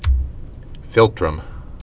(fĭltrəm)